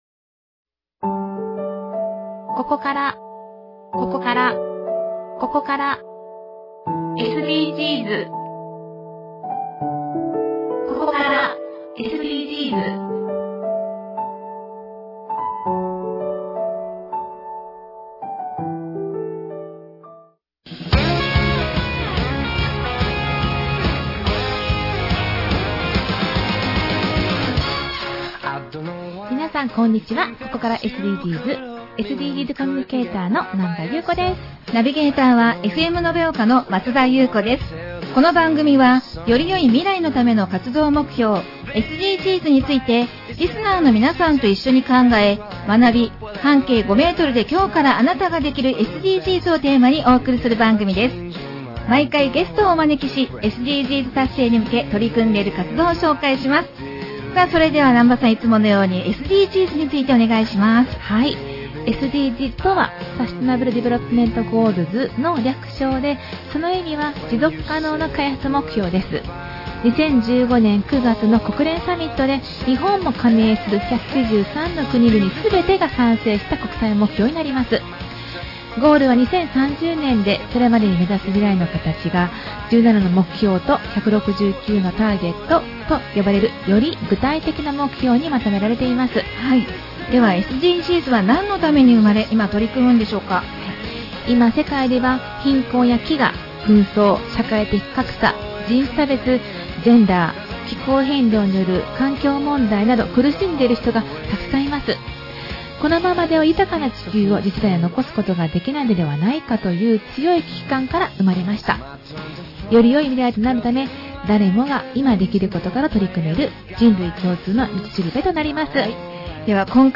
特別番組